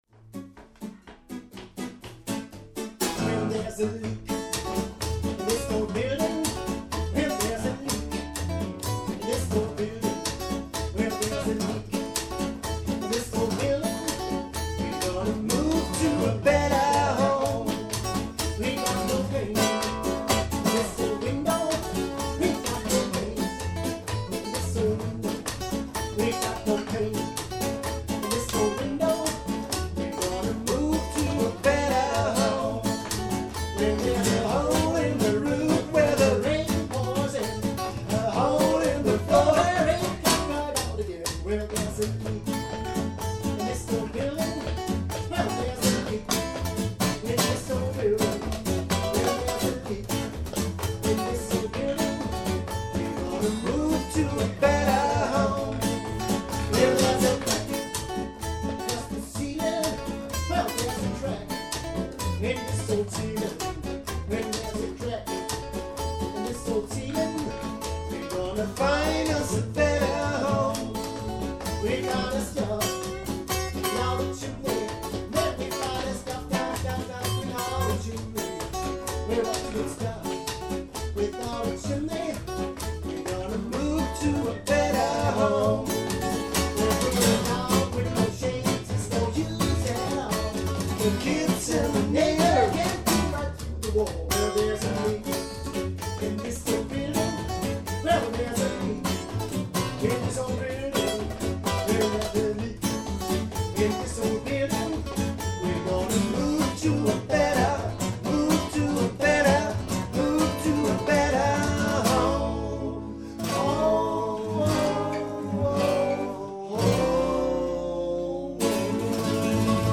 Good Rockin Acoustic Music